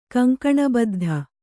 ♪ kaŋkaṇa baddha